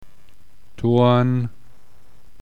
Gemischte Vokale oa und öä, sprich gedehnt, mit offenem o- bzw. ö-Laut